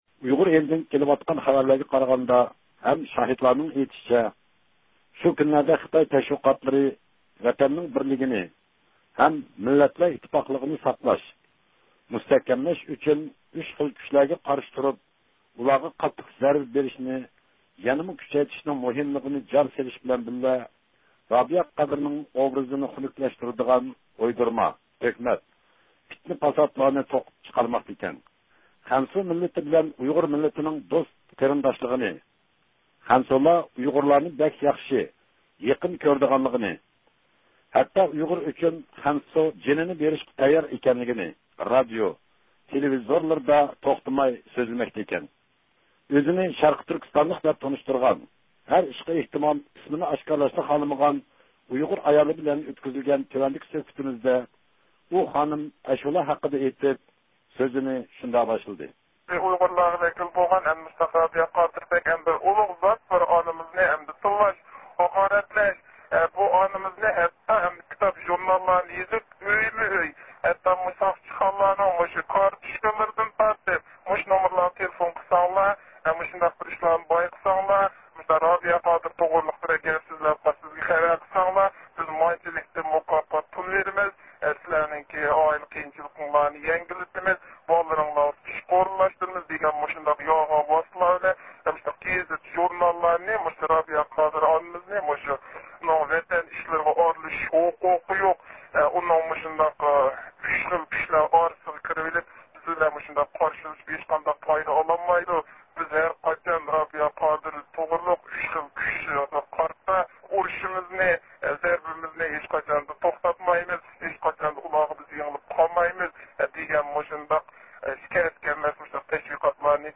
ئۇيغۇر ئىلىدىن قازاقىستانغا زىيارەتكە چىققان بىر نەپەر ئۇيغۇر ئايال، ئۆز ۋەتىنىدىكى سىياسىي ۋەزىيەت ھەققىدە توختالدى.